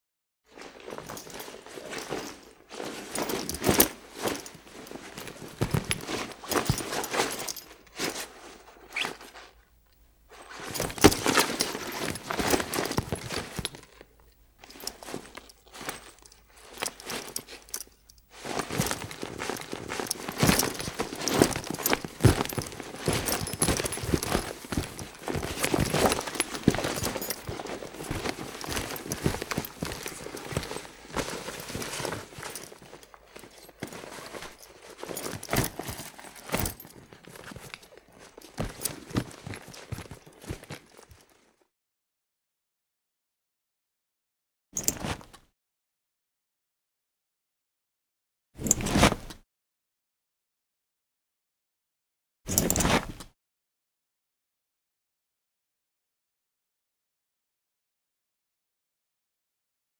Duffle Bag Sound
household